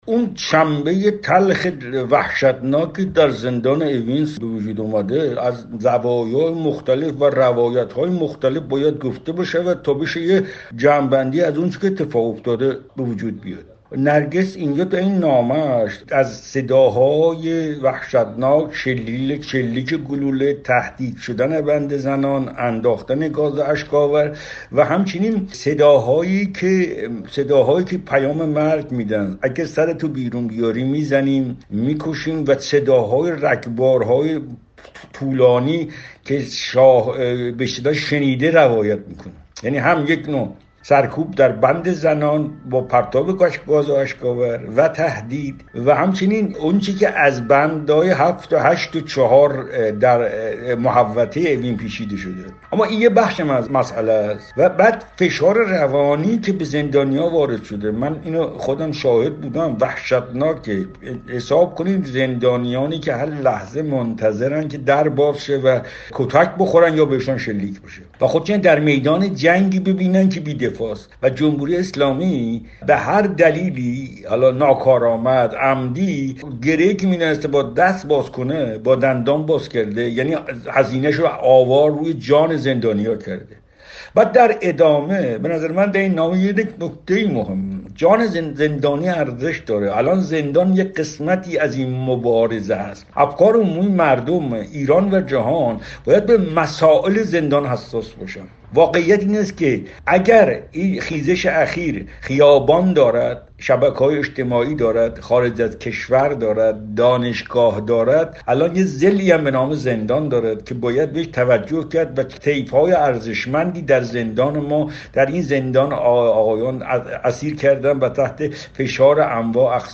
گفت‌وگو با تقی رحمانی درباره گزارش نرگس محمدی